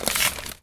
R - Foley 248.wav